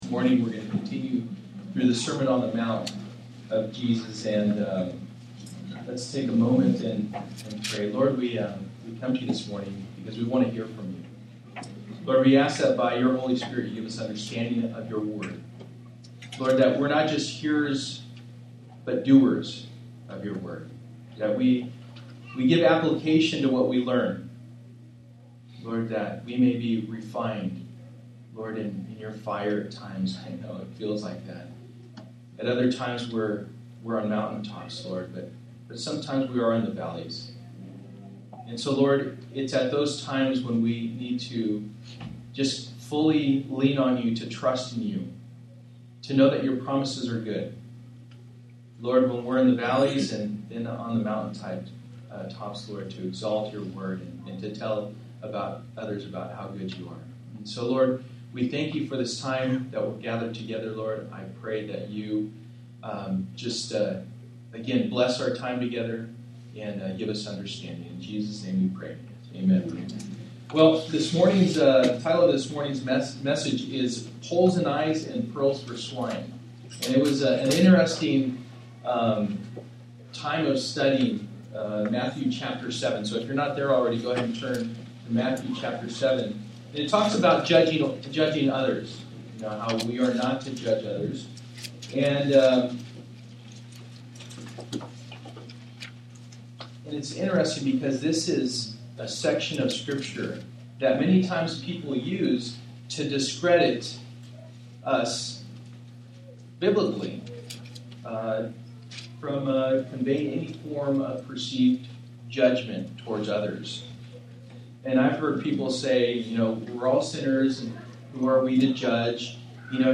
n/a Passage: Matthew 7:1-6 Service: Sunday Morning %todo_render% « Worry-Free Living One Spirit